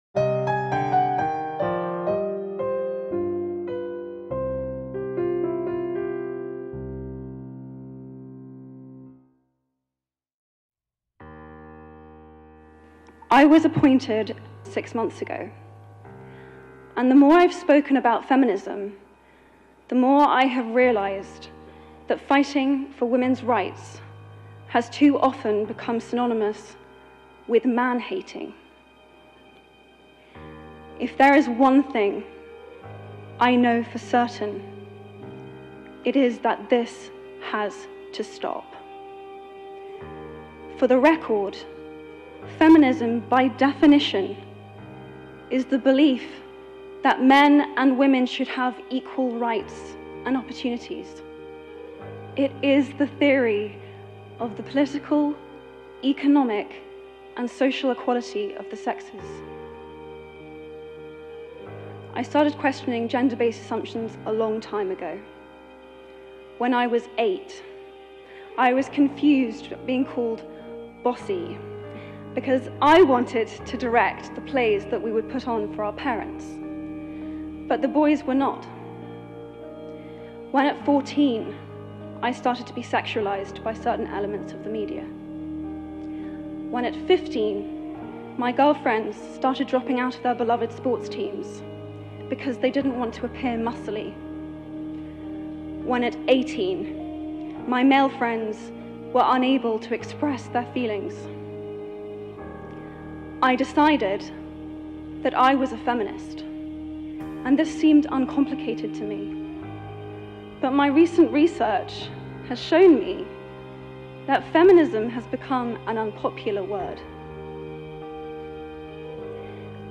KEDj1GZvk6v_emma-watson-speech.m4a